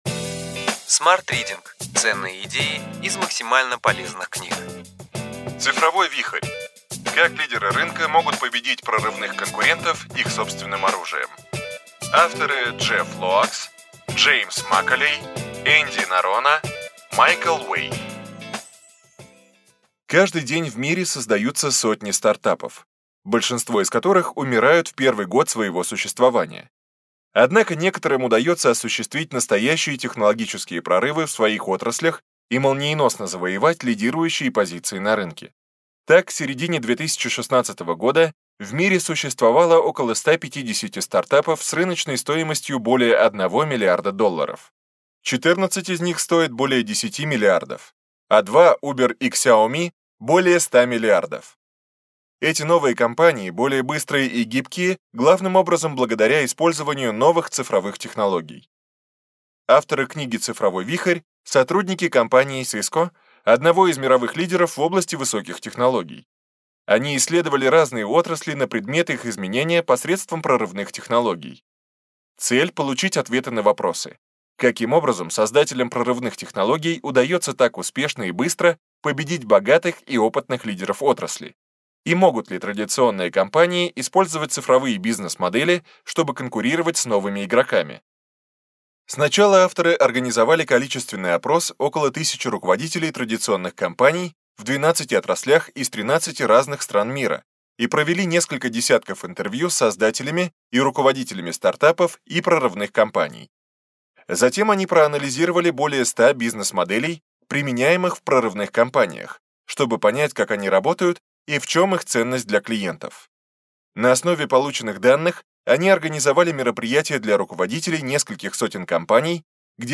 Аудиокнига Ключевые идеи книги: Цифровой вихрь.